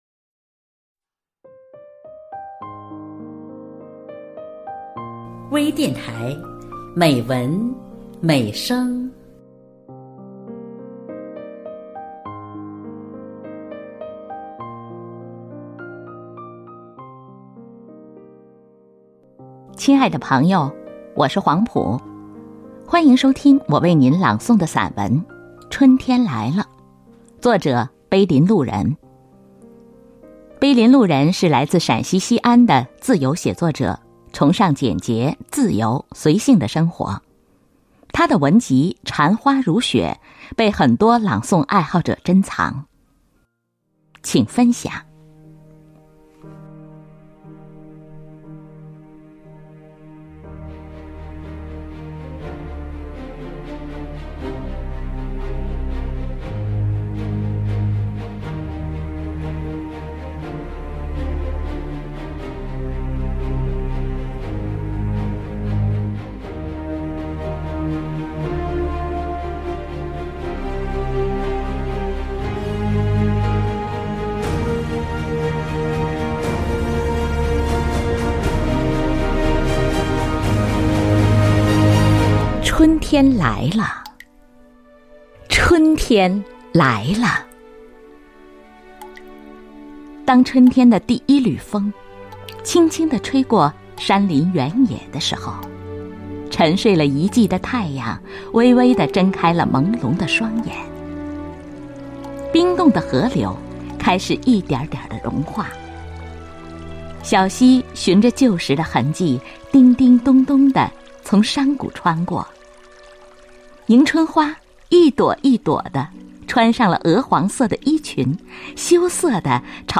多彩美文  专业诵读